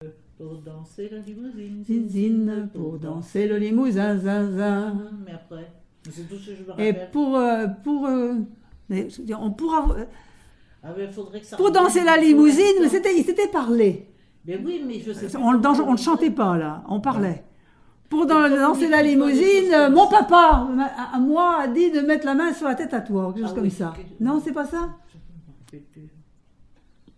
danse : ronde : la limouzine
collectif de chanteuses de chansons traditionnelles
Pièce musicale inédite